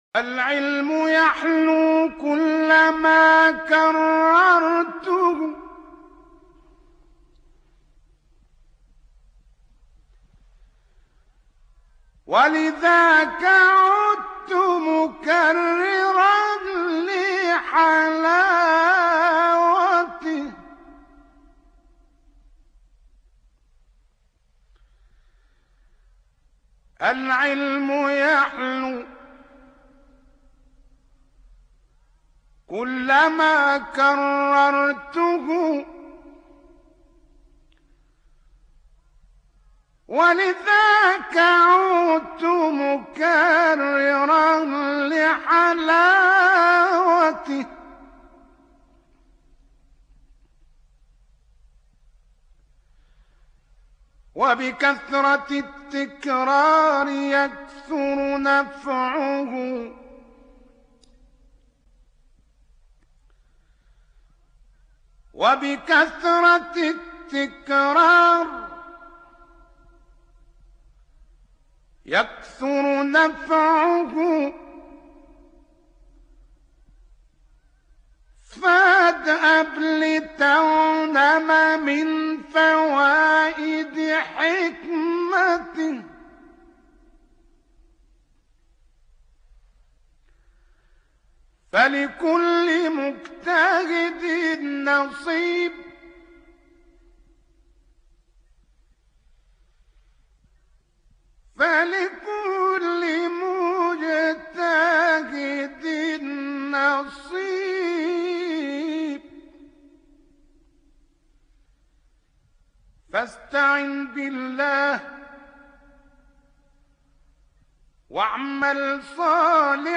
ابتهال زیبا